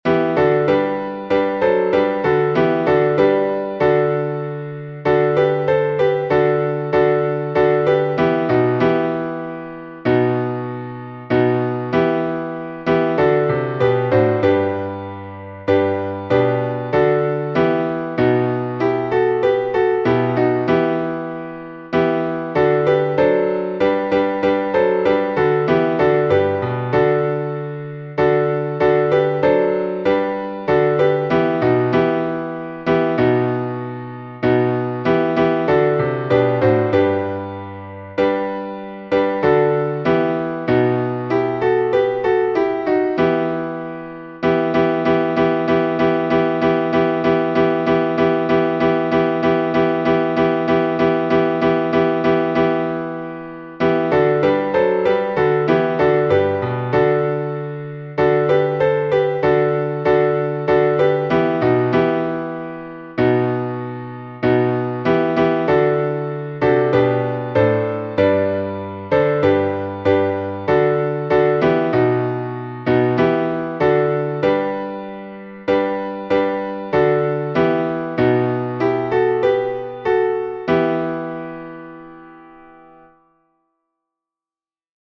glas4_moskovskij.mp3